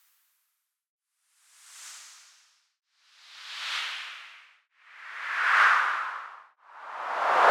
Fancy Sweep 1.wav